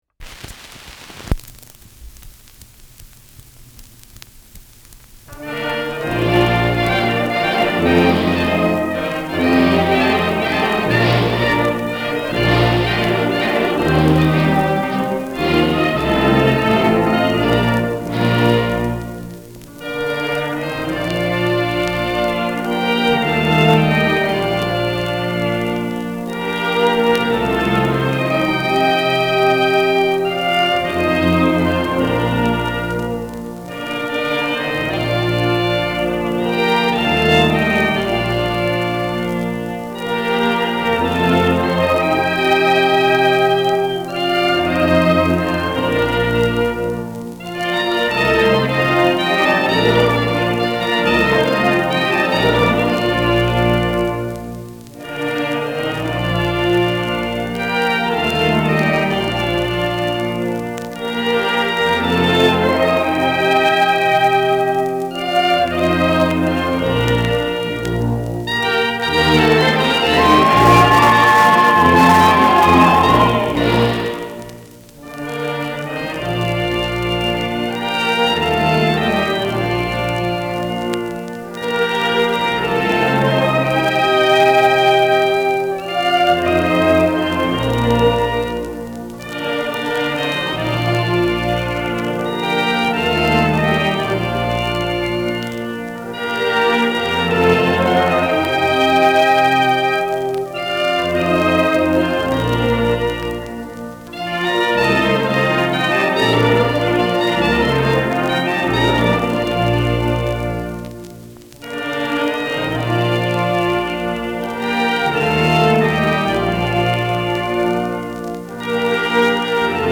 Schellackplatte
Leiern : Vereinzelt leichtes Knacken
Große Besetzung mit viel Hall, die einen „symphonischen Klang“ erzeugt.
[Berlin] (Aufnahmeort)